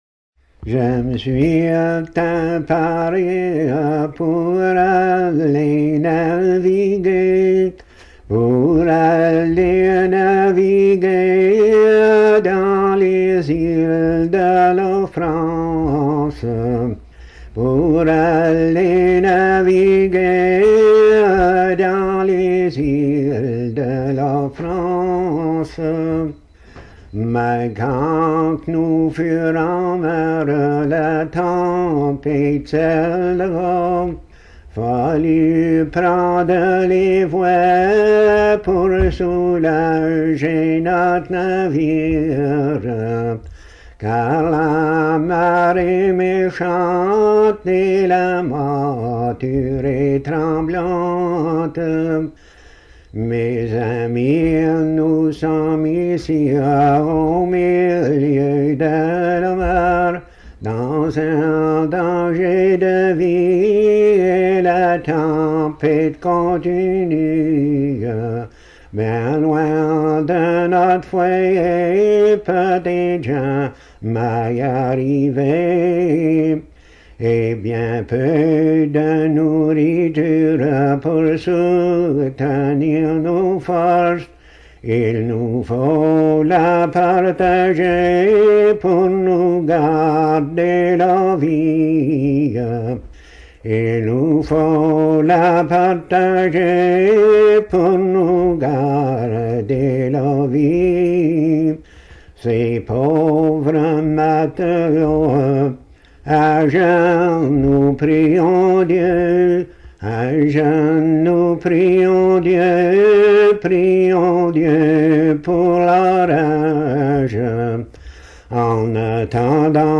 Thème : 0071 - L'armée - Marins
Genre strophique